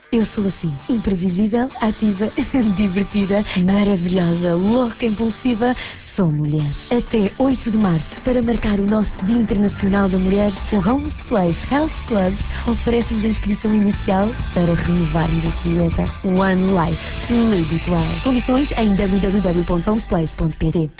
No passado dia 1 de Março estreou uma campanha de rádio, dos ginásios Holmes Place, que divulgava uma promoção dirigida ao público feminino em que se oferecia a inscrição nos ginásios da cadeia.